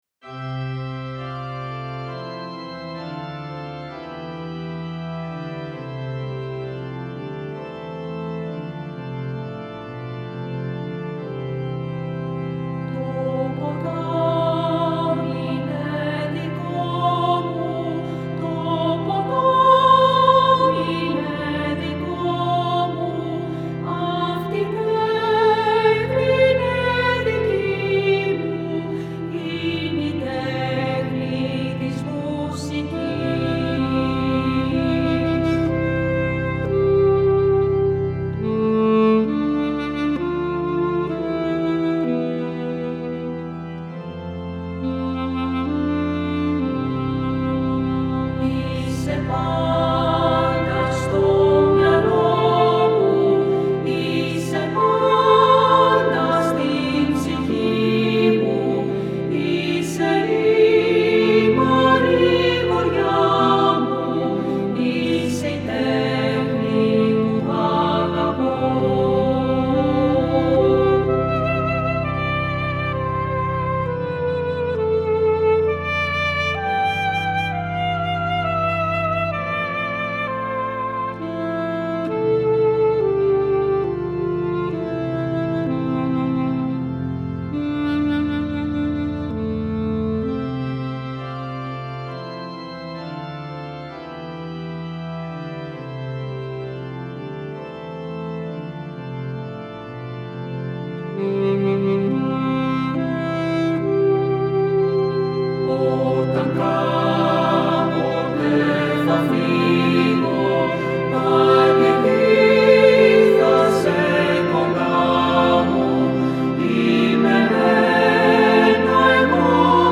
Χορωδία